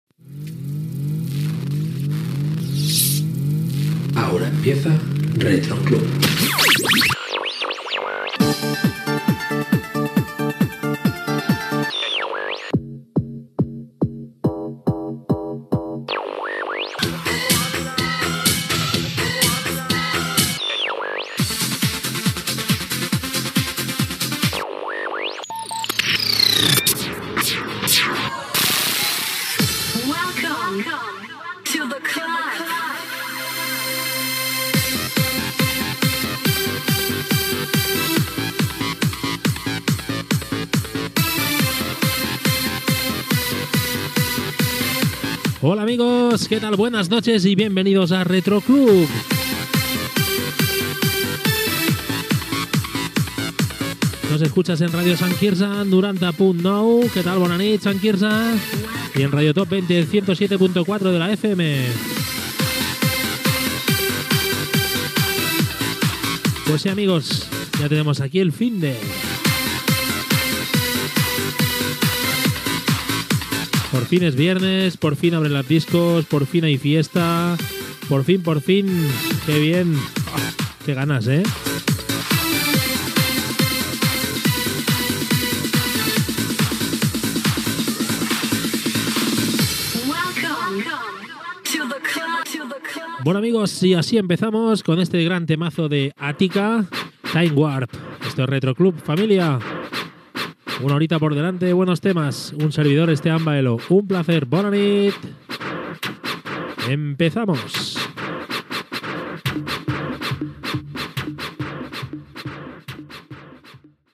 Sintonia del programa, presenció amb la identificació de les dues emissores que emeten el programa i tema musical
Musical